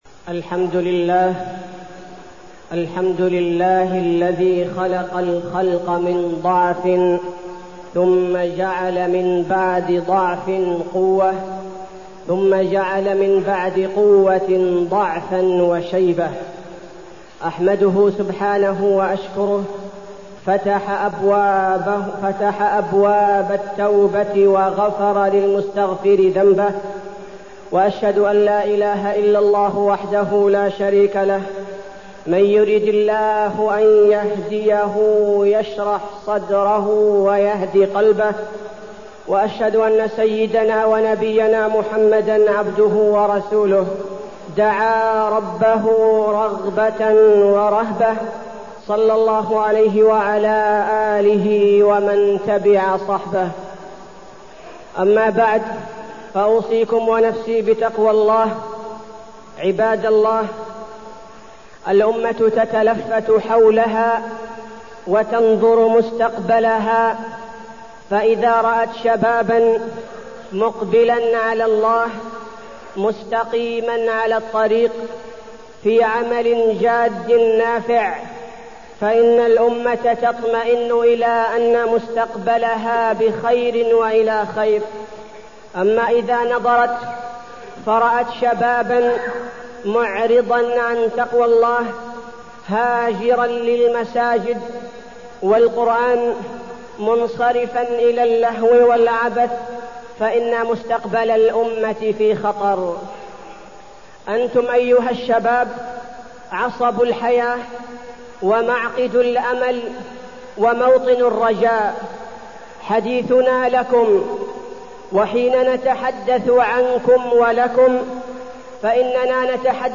تاريخ النشر ٧ جمادى الآخرة ١٤٢٠ هـ المكان: المسجد النبوي الشيخ: فضيلة الشيخ عبدالباري الثبيتي فضيلة الشيخ عبدالباري الثبيتي الشباب